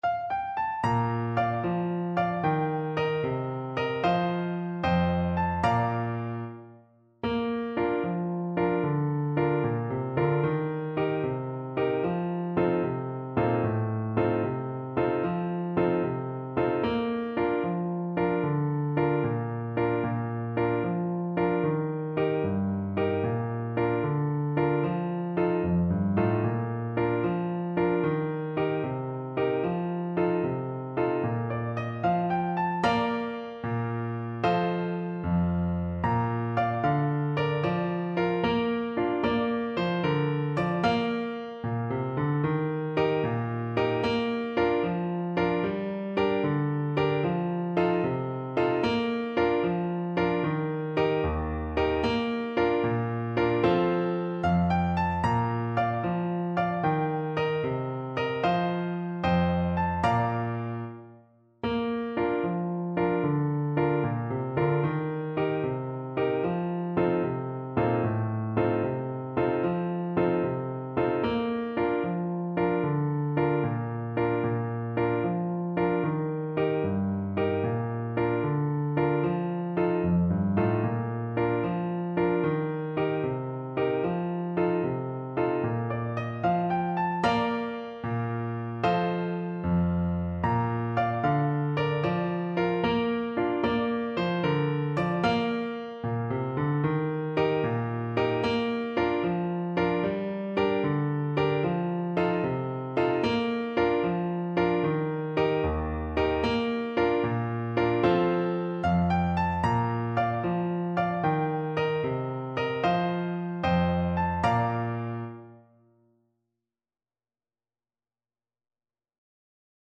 Clarinet
6/8 (View more 6/8 Music)
Allegro .=c.100 (View more music marked Allegro)
Traditional (View more Traditional Clarinet Music)